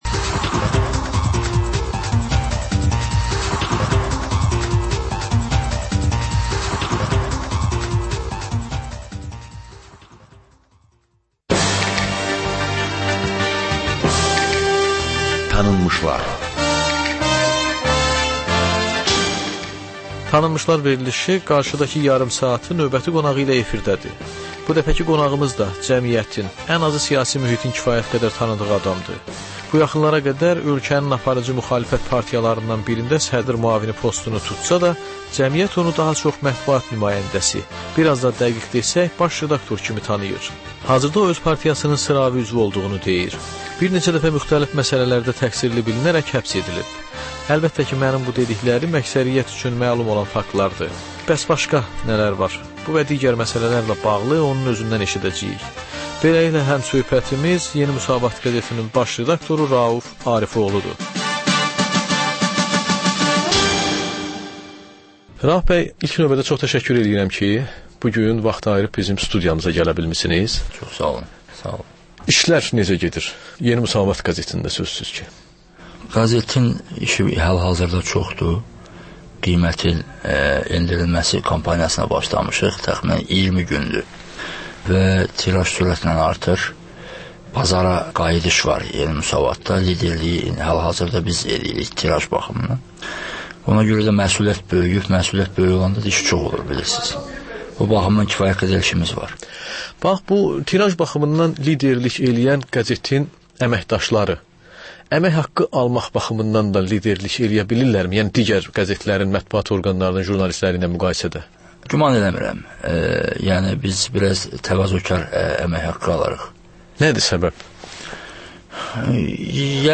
Hadisələr, reportajlar. Panorama: Jurnalistlərlə həftənin xəbər adamı hadisələri müzakirə edir.